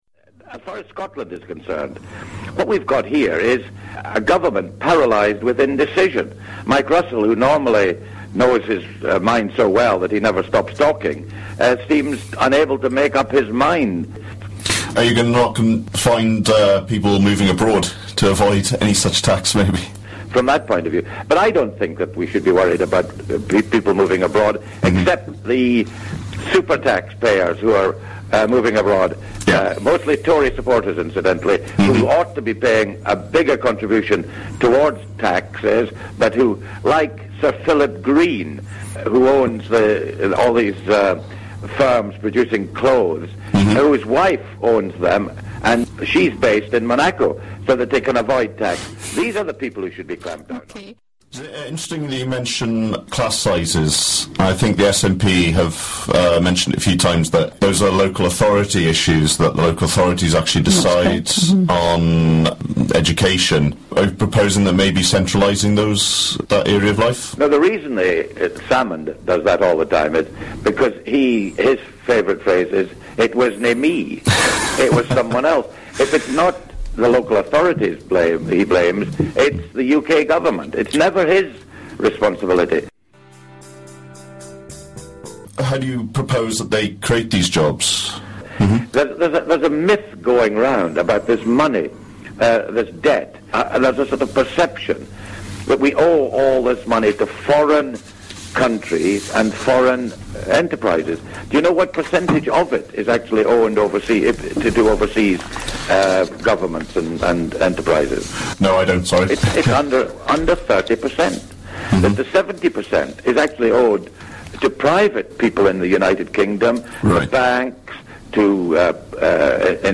Highlights of interview with Lord George Foulkes